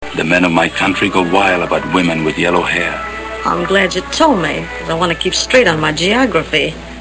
Tags: Mae West Mae West movie clips Come up and see me some time Mae West sound Movie star